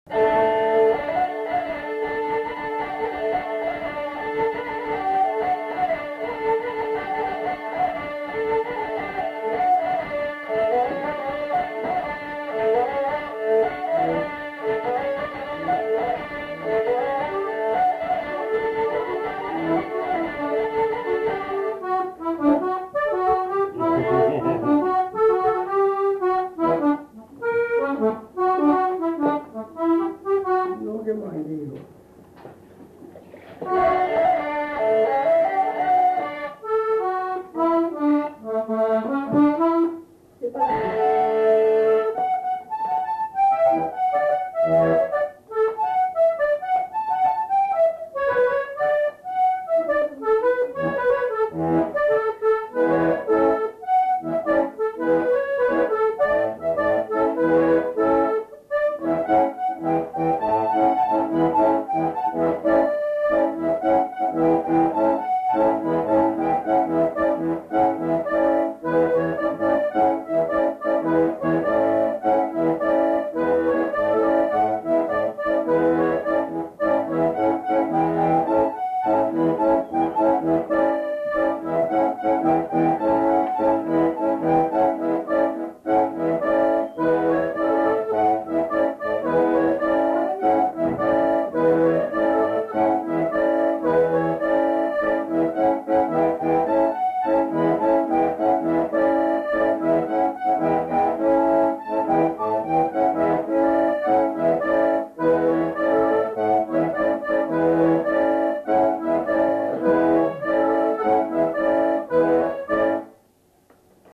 Lieu : Pindères
Genre : morceau instrumental
Instrument de musique : accordéon diatonique ; vielle à roue
Danse : rondeau